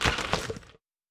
Net Swoosh Normal.wav